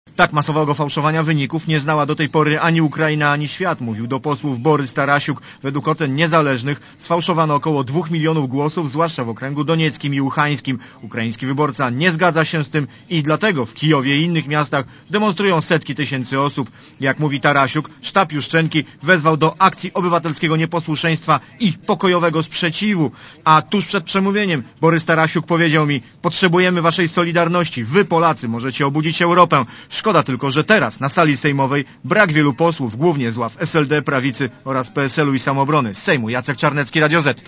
Borys Tarasiuk, przemawiając przed południem w Sejmie,